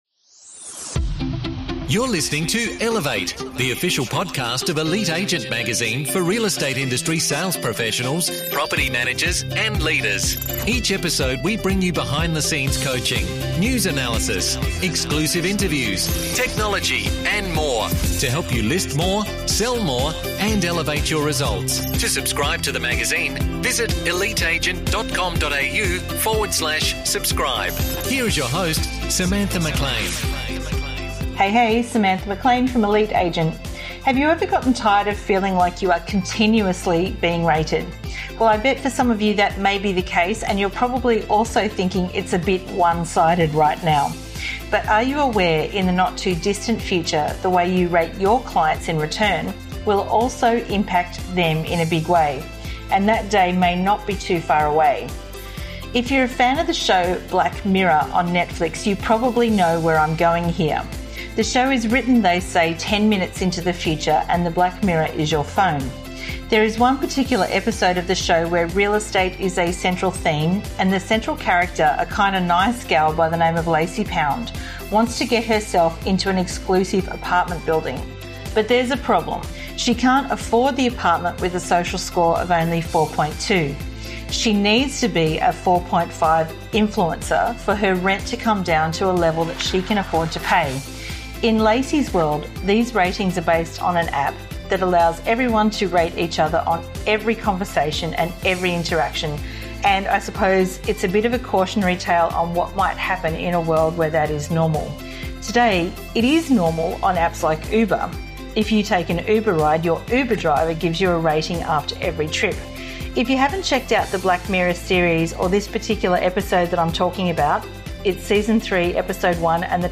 Guest Interview